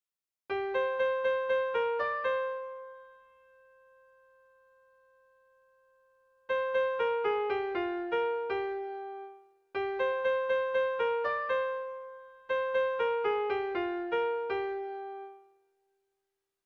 Kopla handiaren moldekoa
ABAB